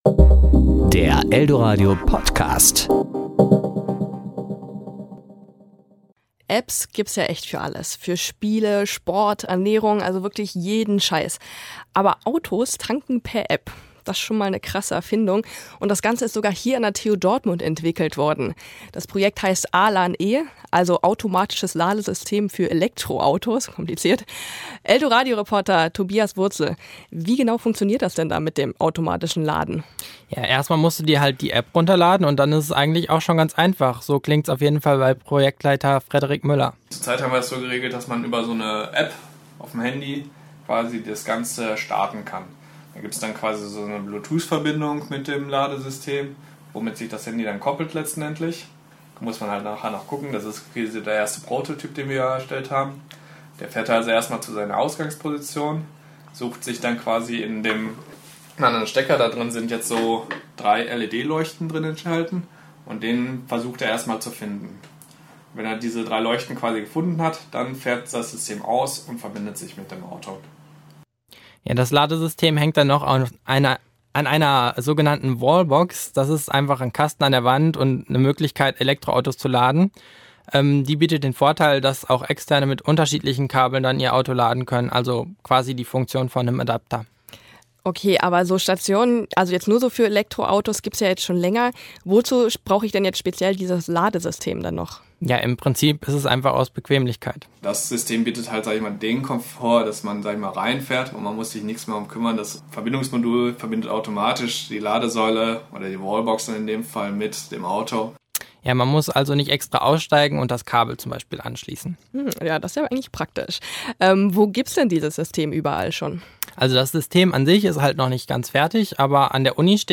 Kollegengespräch  Sendung